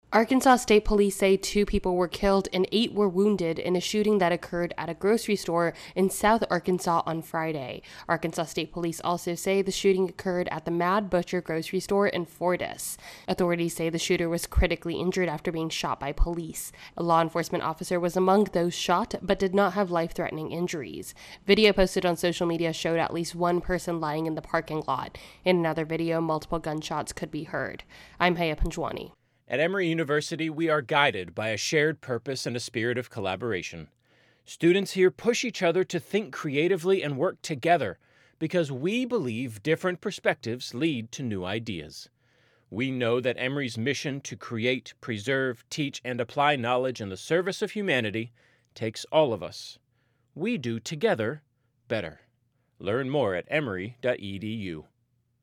reports on a fatal grocery store shooting in Arkansas.